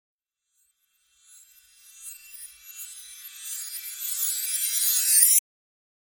Mystical Reverse Bell Tones - Enchanted Transition Sound Effect
Magical and dreamy sound effect, perfect for videos, games, YouTube, TikTok, reels, animations, and multimedia projects that need enchanting audio transitions Listen and download in MP3 format.
Genres: Sound Effects
Mystical-reverse-bell-tones-enchanted-transition-sound-effect.mp3